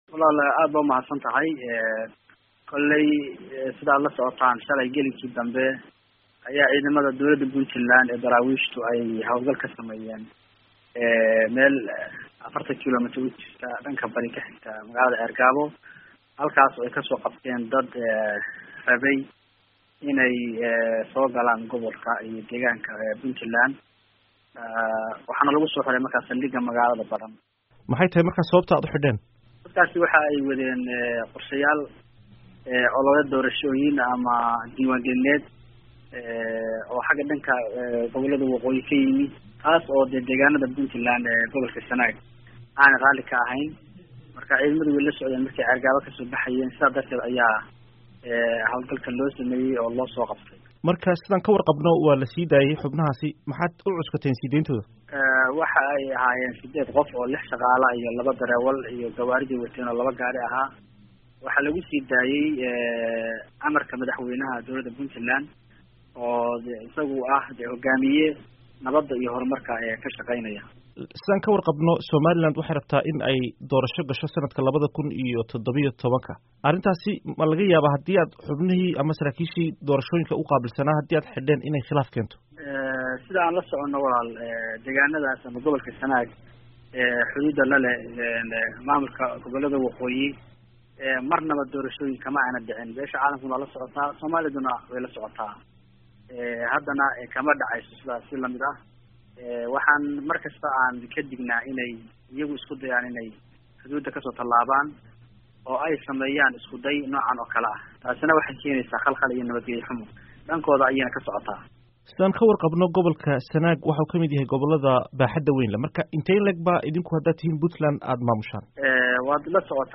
Guddoomiyaha gobolka Sanaag ee maamulka Puntland Maxamuud Ismaaciil CIYOON, oo wareysi siiyey VOA-da ayaa sharraxaad ka bixiyey sababta ay ciidamada Puntland u afduubteen xubno Somaliland ka socday.
Wareysi: Guddomiyaha Sanaag ee Puntland